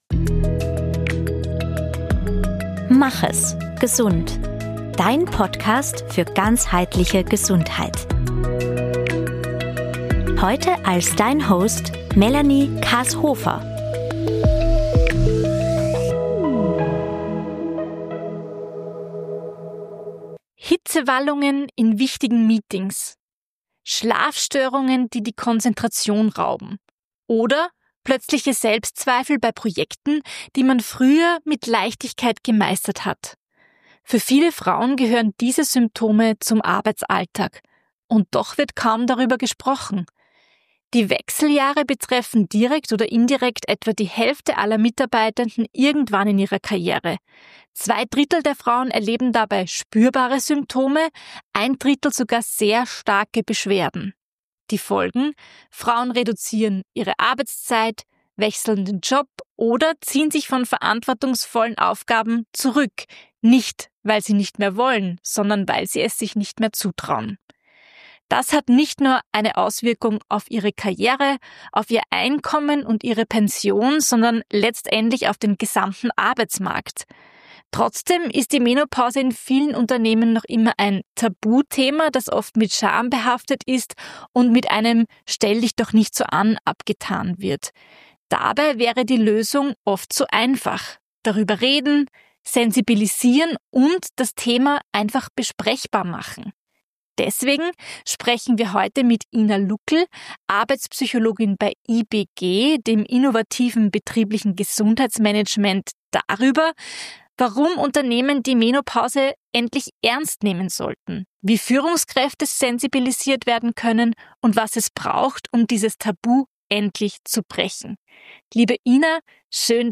Ein wichtiges Gespräch über Frauengesundheit, Verantwortung im Arbeitskontext und die Chance, Arbeitswelten so zu gestalten, dass Frauen auch in den Wechseljahren sichtbar, wirksam und gesund bleiben können.